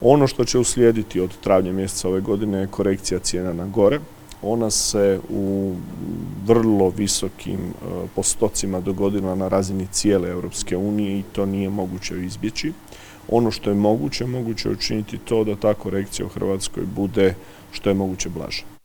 SPLIT - U idućim mjesecima će doći do poskupljenja energenata, pa građani mogu očekivati skuplje režije za plin i struju. Ministar gospodarstva Tomislav Ćorić je poručio da u Vladi čine sve da ublaže rast cijena.